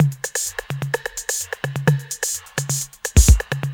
Index of /VEE/VEE Electro Loops 128 BPM
VEE Electro Loop 087.wav